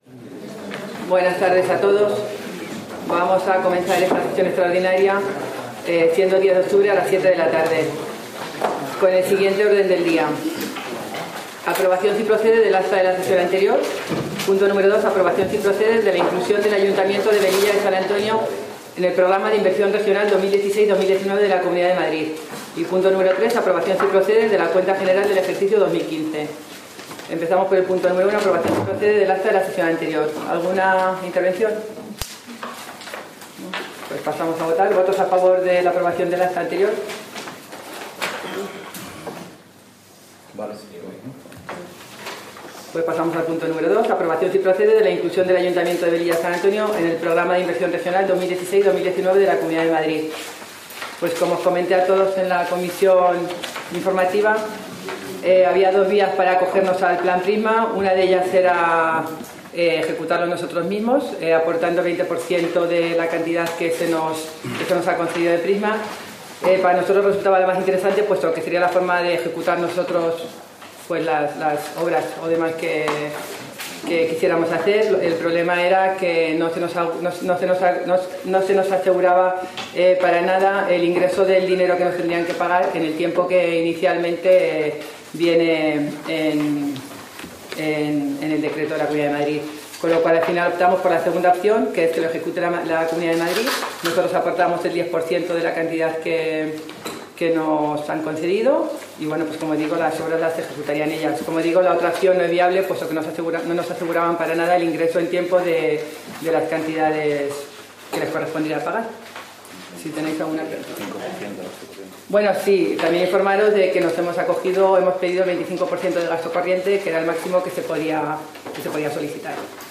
Pleno ordinario de 20 de septiembre de 2017